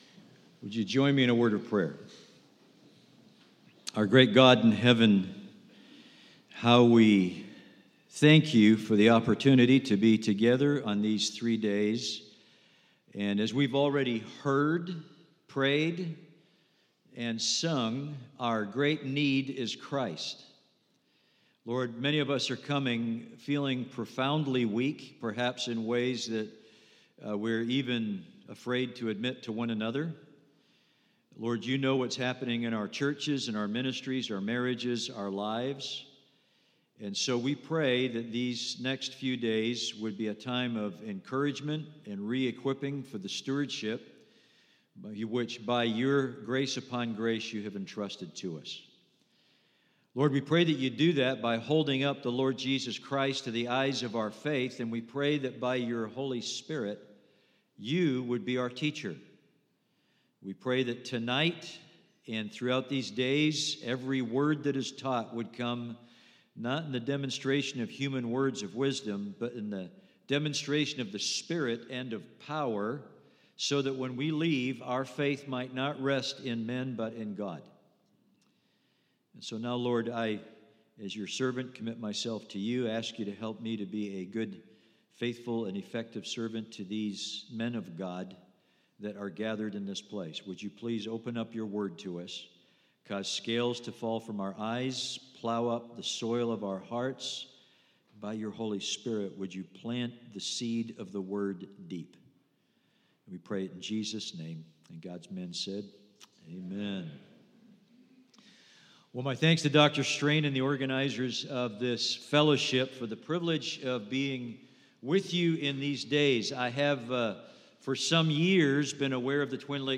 Worship Service 1: An Awakened Ministry is a Christ-Centered Ministry